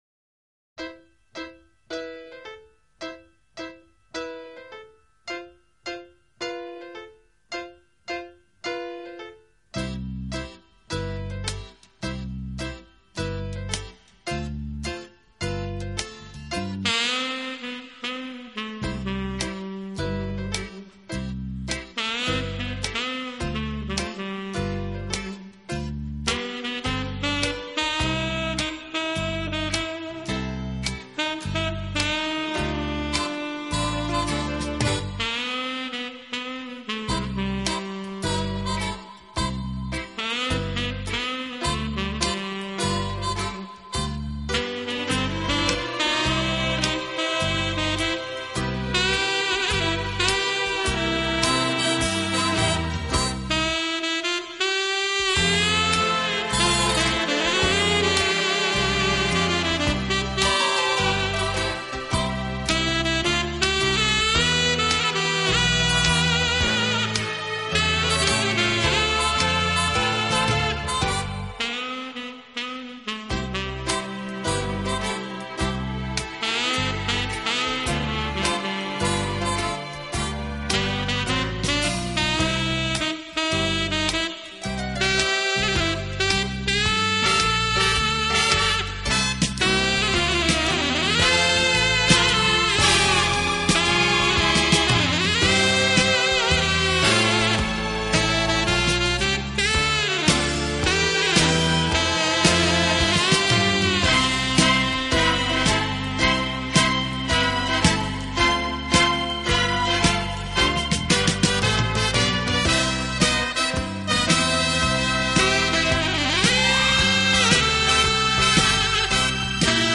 音乐类型：Instrumental
的演奏，适合用于迪斯科舞厅。那种“脚踩踏音乐”的效果，冲击的节奏